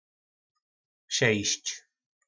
Slovník nářečí Po našimu
Šest - Šejišč